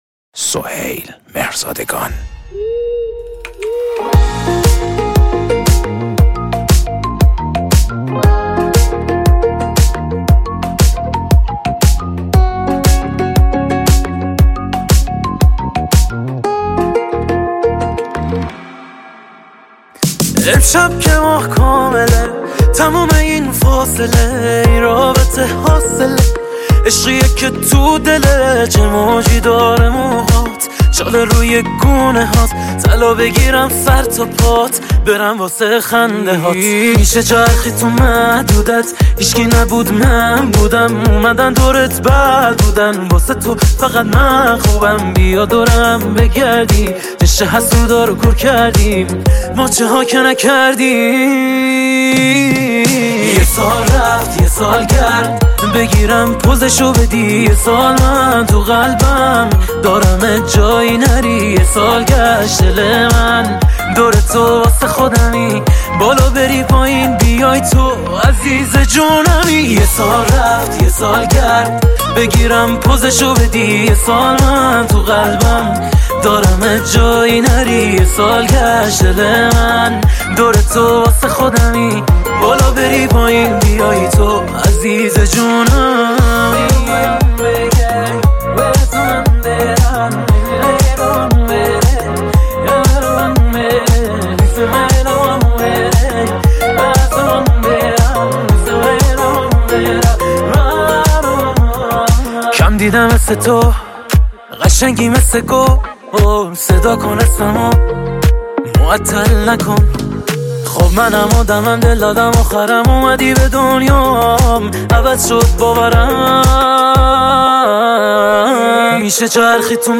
پاپ شاد عاشقانه غمگین